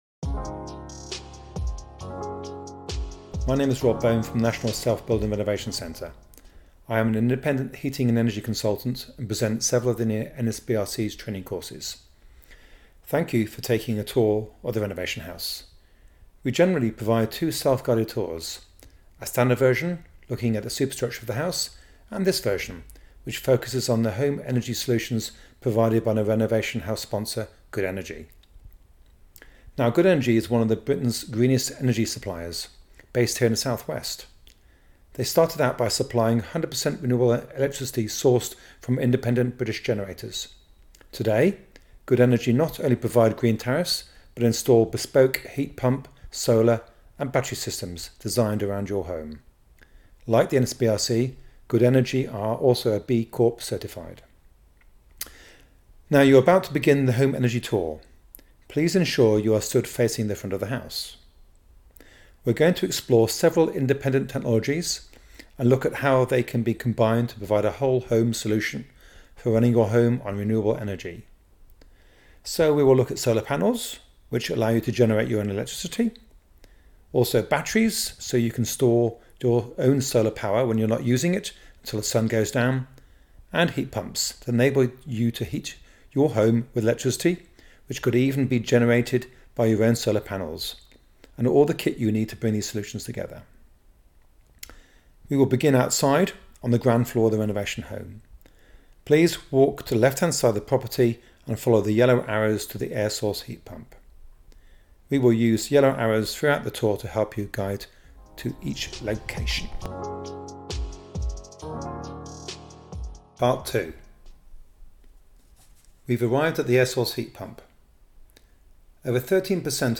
Good_Energy_Audio_Tour_-_NSBRC_Renovation_House_-_Oct_2025.mp3